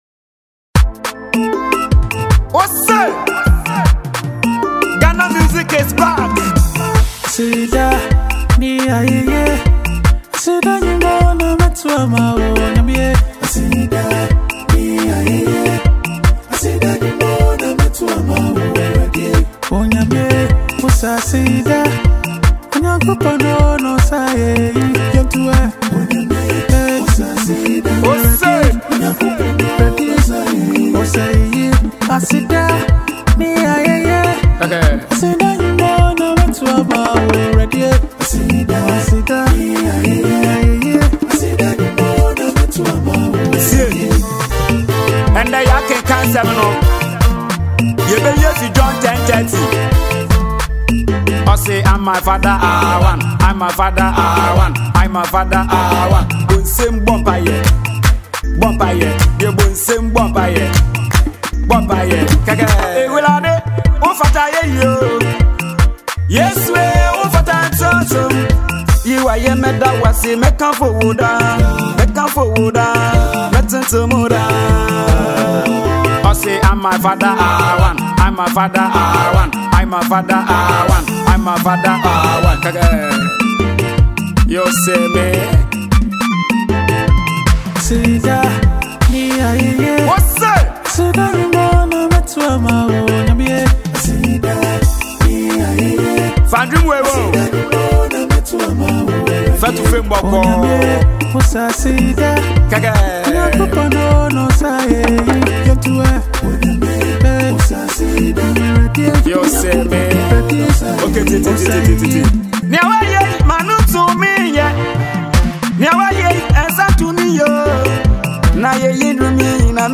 a danceable mp3 download song
Ghana Afrobeat MP3